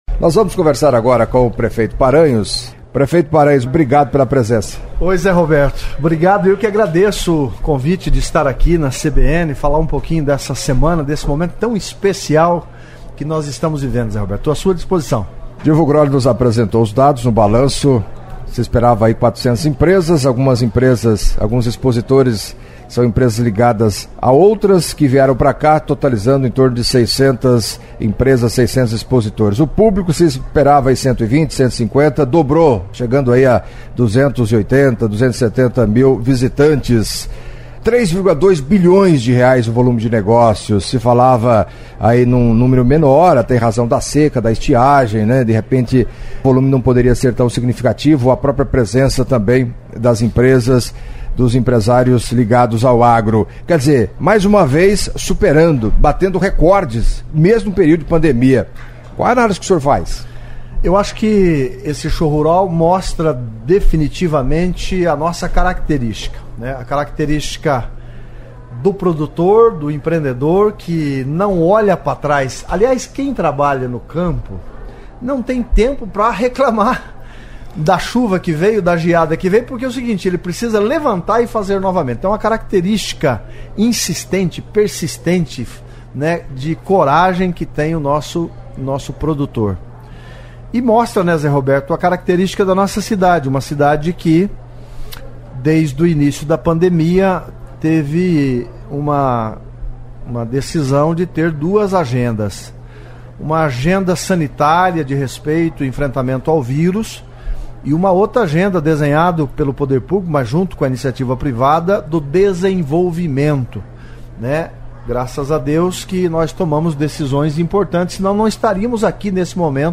Em entrevista à CBN nesta sexta-feira (11) o prefeito de Cascavel, Leonaldo Paranhos, destacou a importância do Show Rural para o município e, entre outros assuntos, falou da possibilidade de filiar-se ao Podemos.